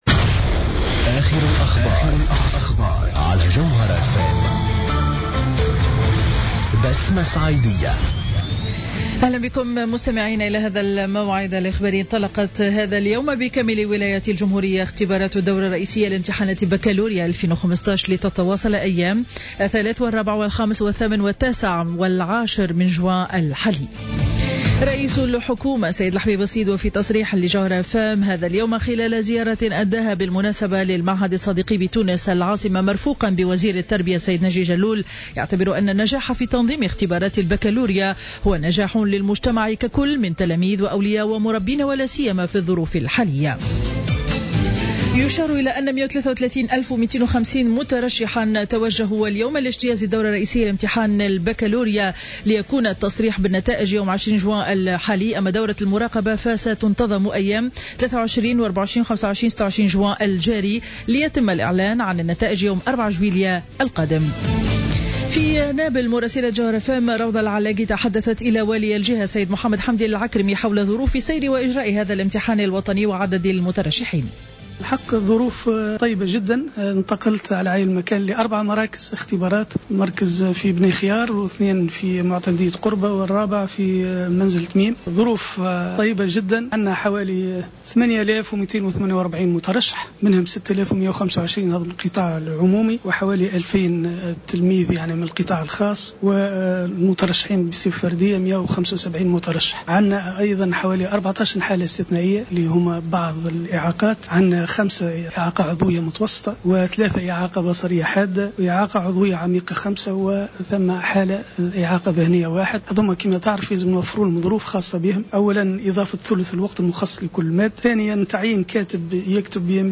نشرة الأخبار منتصف النهار ليوم الإربعاء 03 جوان 2015